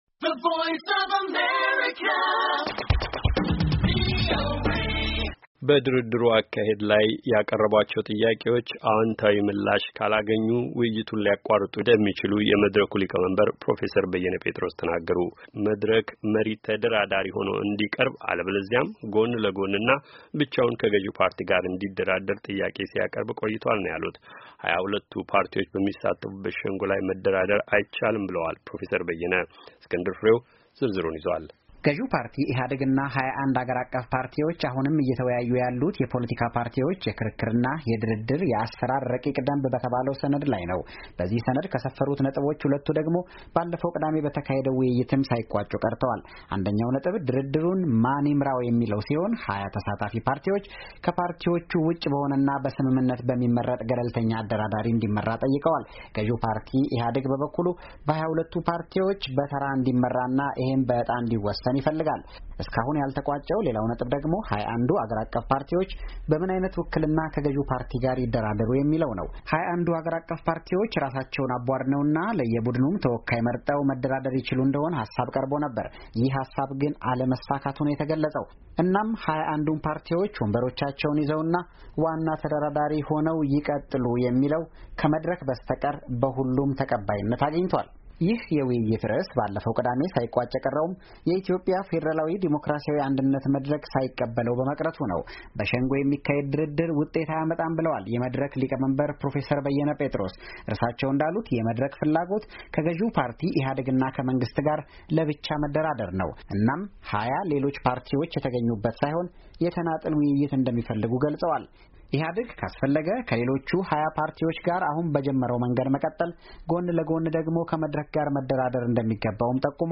ዝርዝር ዘገባ አለው።